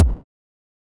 eleDrum03.wav